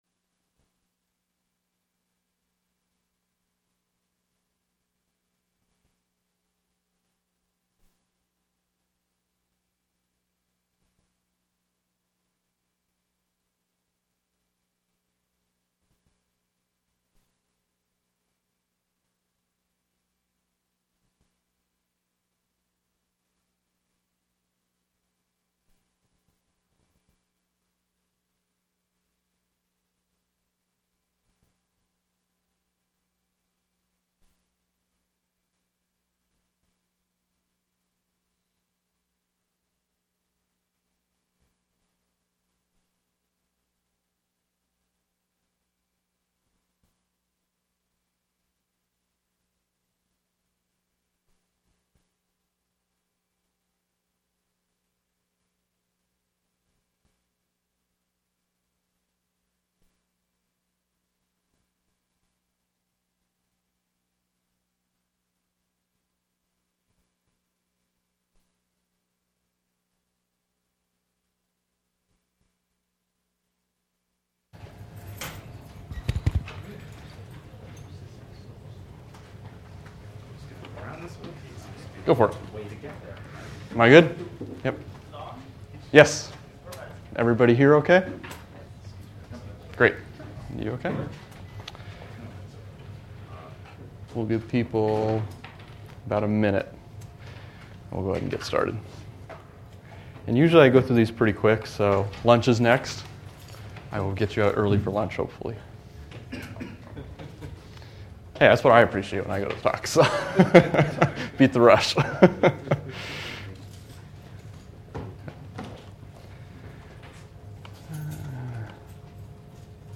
Event type Lecture